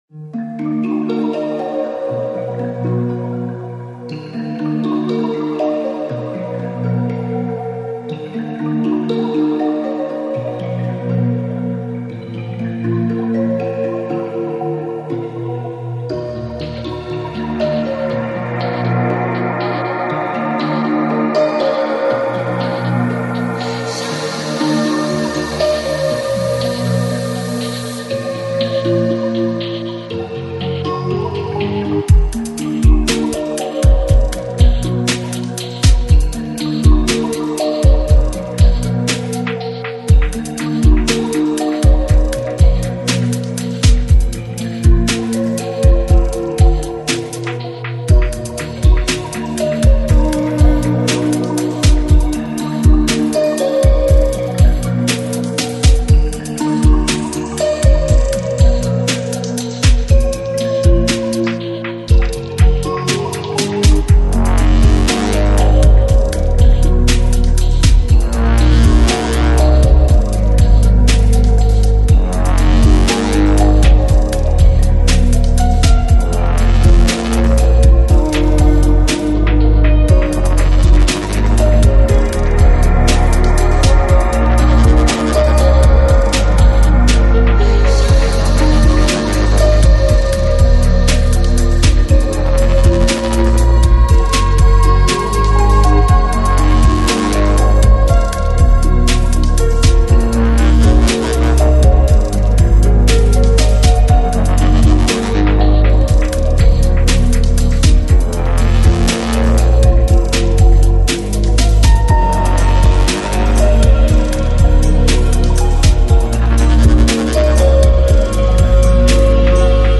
Жанр: Ambient, PsyChill